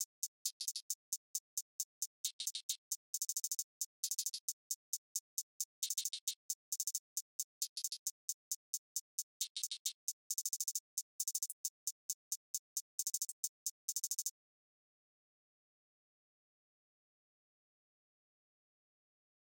Boomin-Beat-Starter-0_Hi Hat Closed.wav